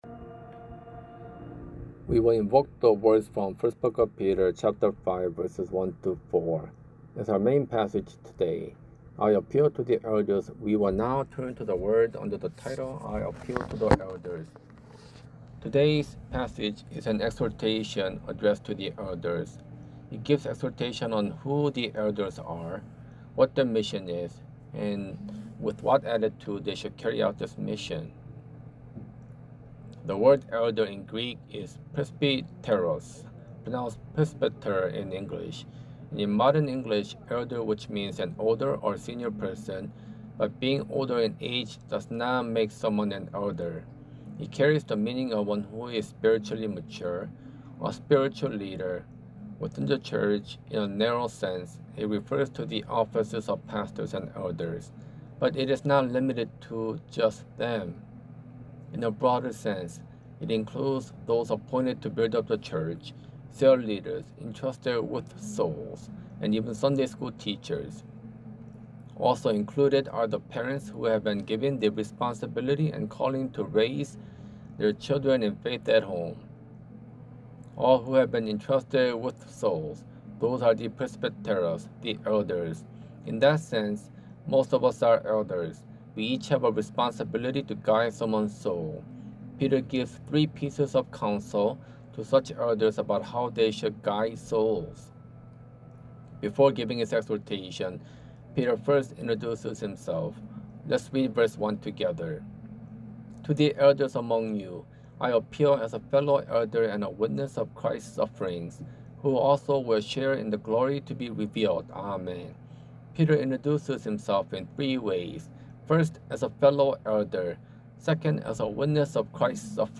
설교
주일예배